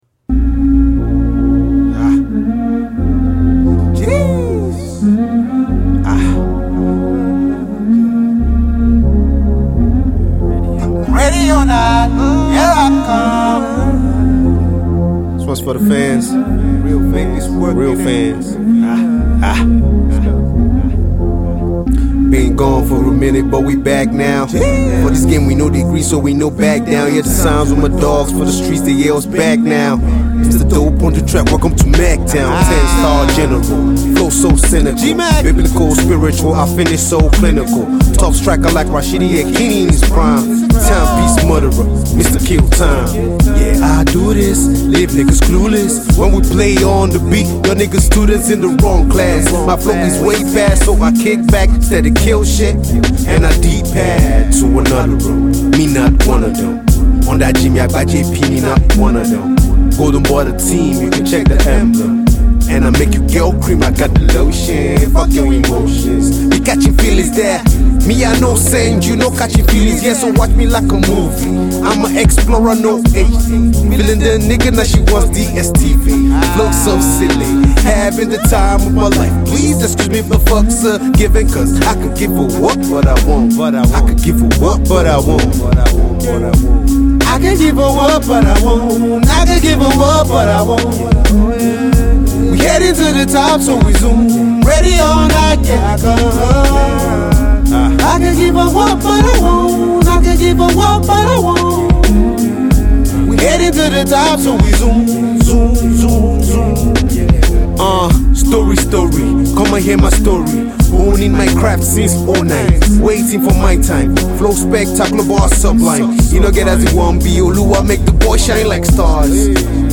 Nigerian rapper